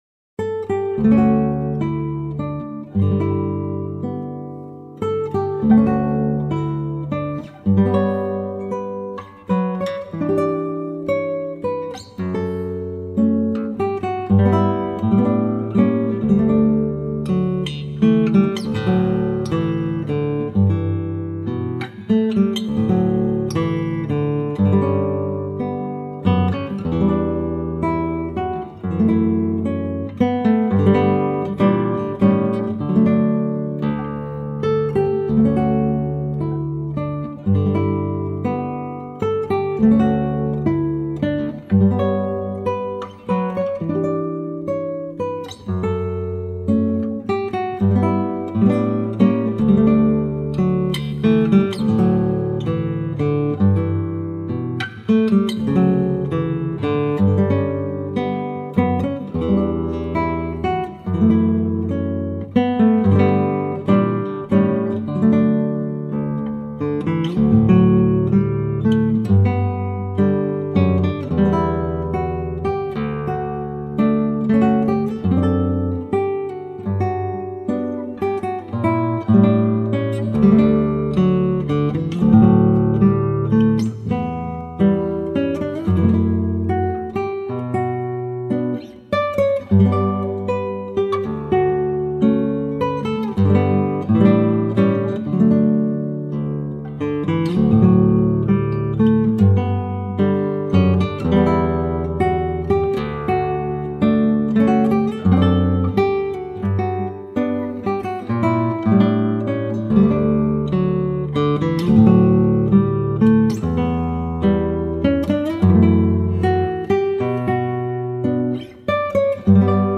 327   04:14:00   Faixa:     Instrumental